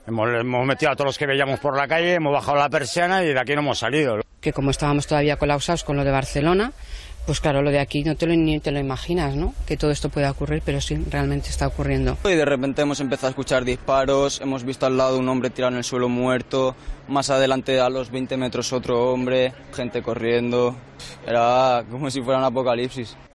“Hemos empezado a escuchar disparos”: testigos relatan a COPE lo vivido en Cambrils